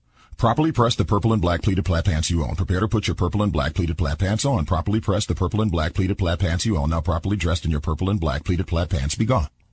tongue_twister_06_03.mp3